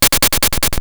effect_fly.wav